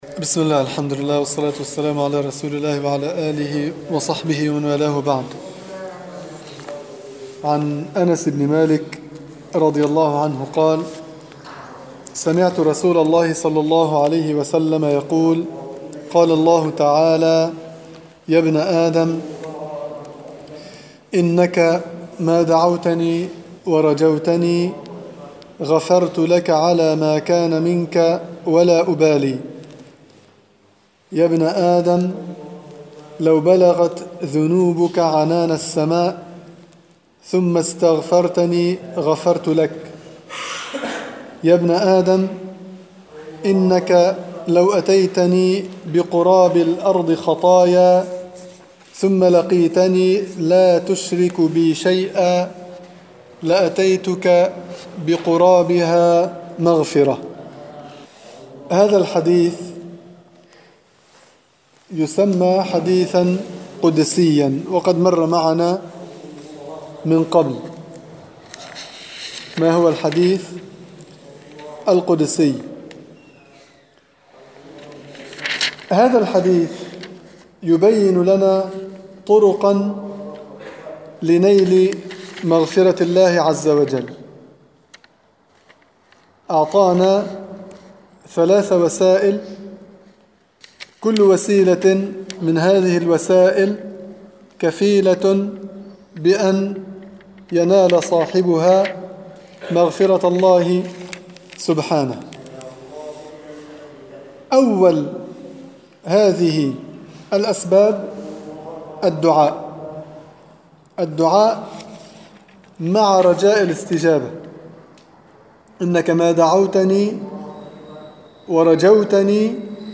دروس تفسير الحديث
في مسجد القلمون الغربي